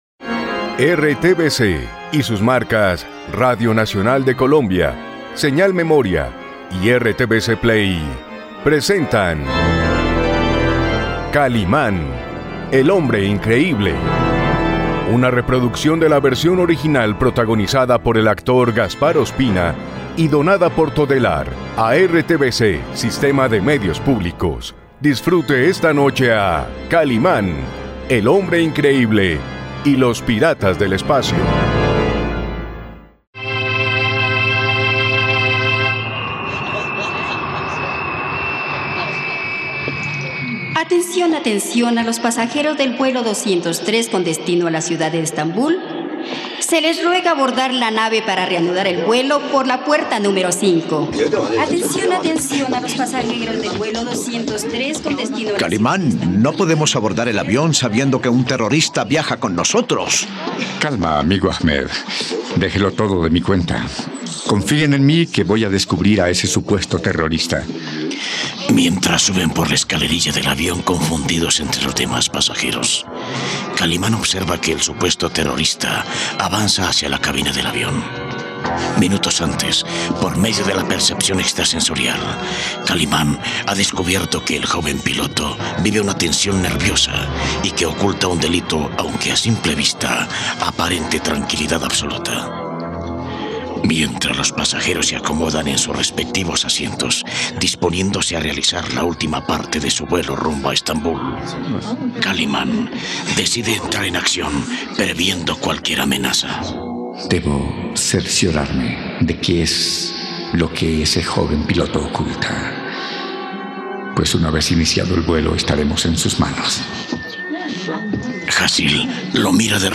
..Disfruta de una nueva aventura junto al hombre increíble, en RTVCPlay puedes disfrutar de la radionovela completa de 'Kalimán y los piratas del espacio'.